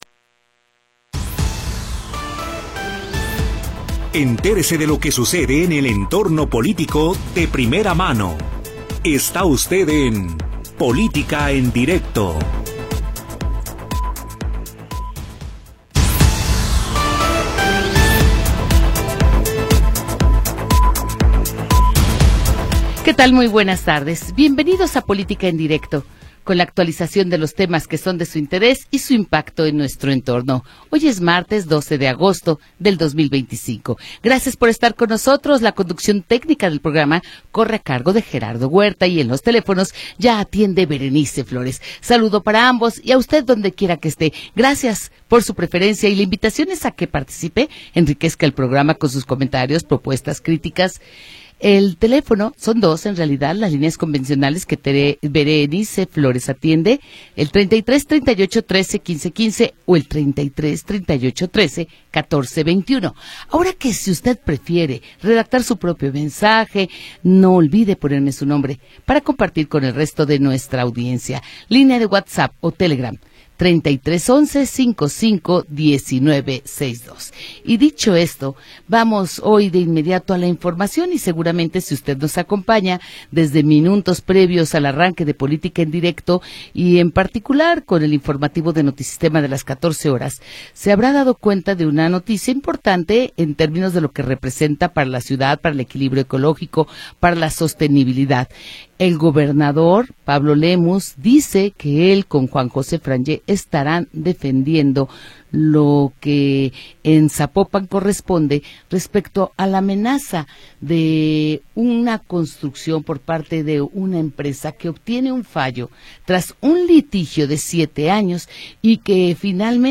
Entérese de todo lo que sucede en el entorno político. Comentarios, entrevistas, análisis y todo lo que a usted le interesa saber